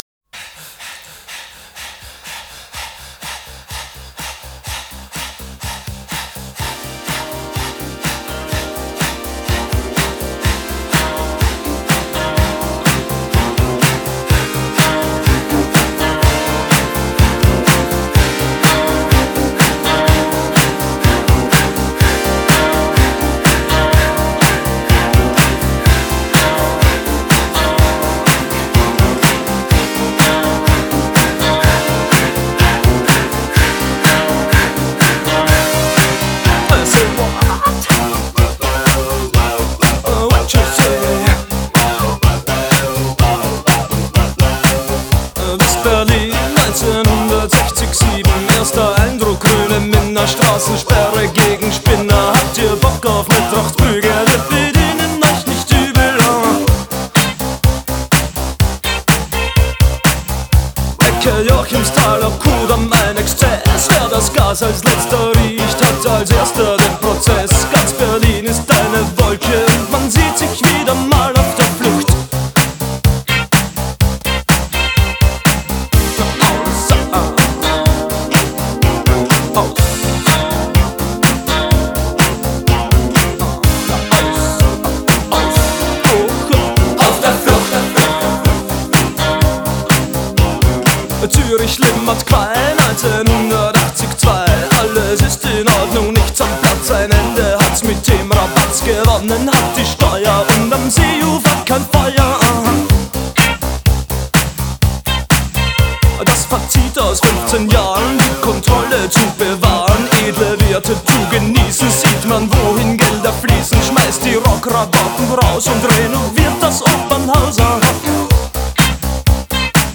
Genre: Rock,New Wave